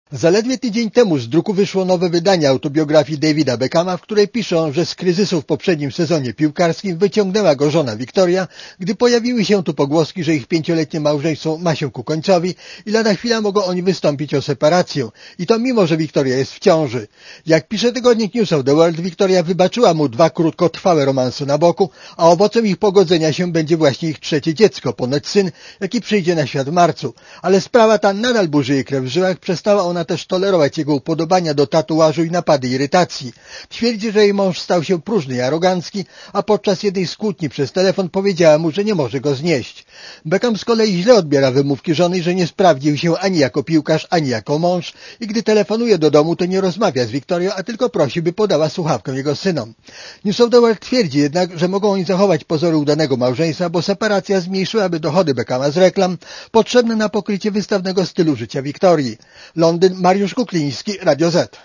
Korespondencja z Londynu